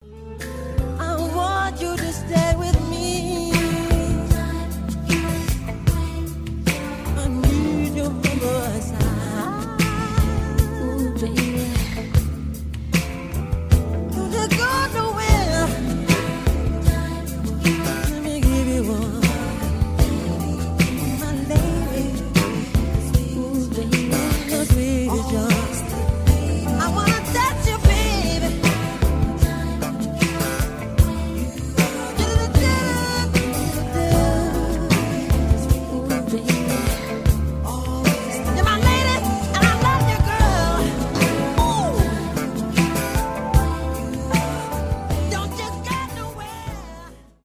романтические , поп
rnb